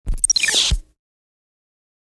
دانلود صدای ربات 1 از ساعد نیوز با لینک مستقیم و کیفیت بالا
جلوه های صوتی